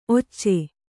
♪ occe